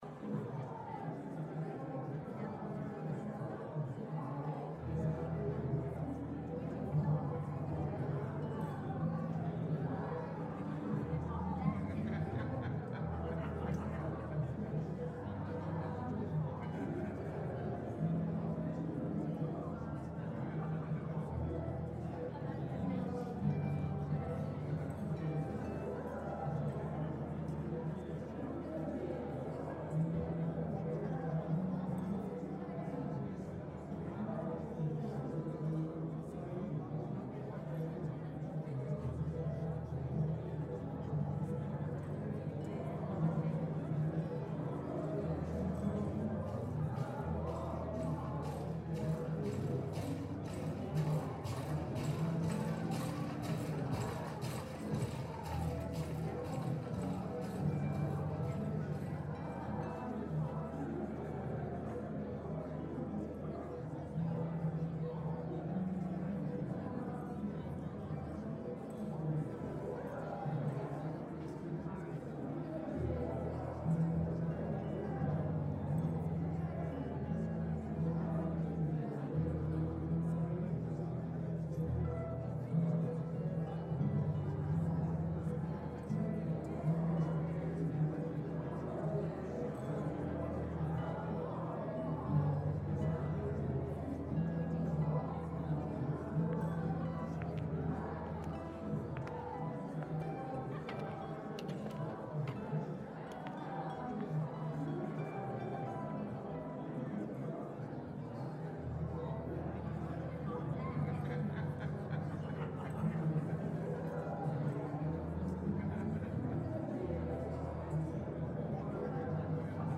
mansionharpambience.ogg